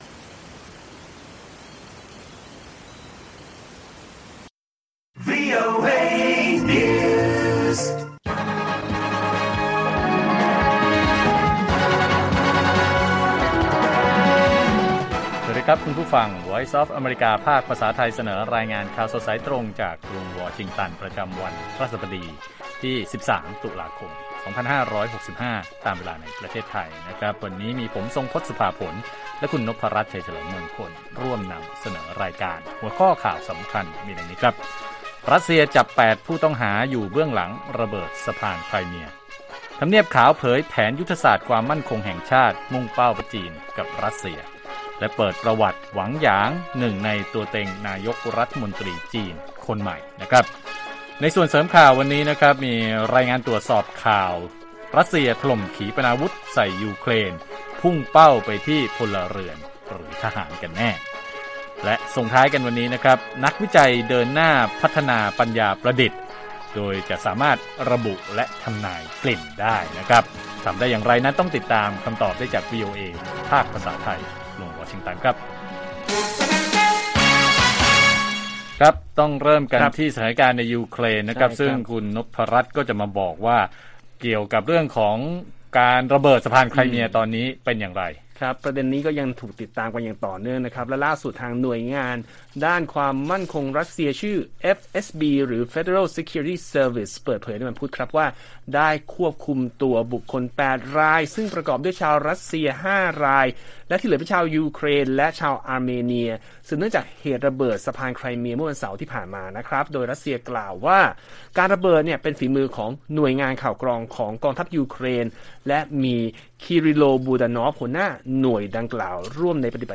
ข่าวสดสายตรงจากวีโอเอไทย พฤหัสบดี 13 ต.ค. 65